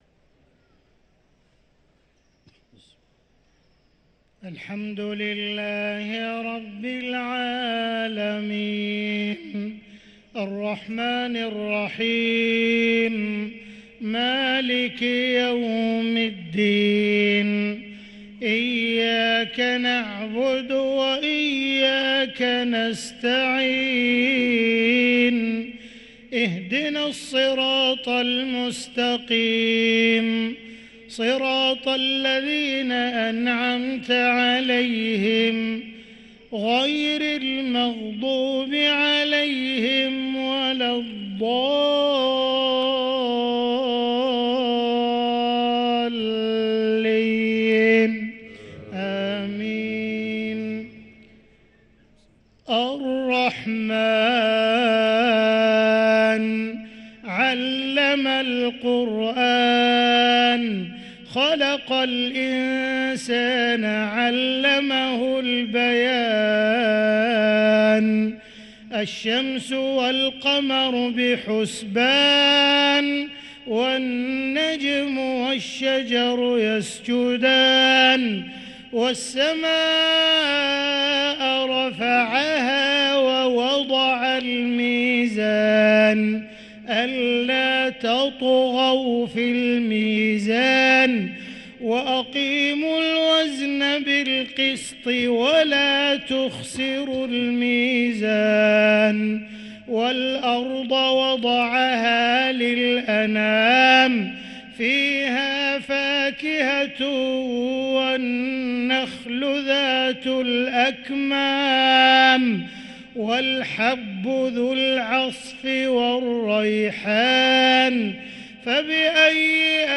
صلاة المغرب للقارئ عبدالرحمن السديس 29 رمضان 1444 هـ
تِلَاوَات الْحَرَمَيْن .